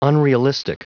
Prononciation du mot unrealistic en anglais (fichier audio)
Prononciation du mot : unrealistic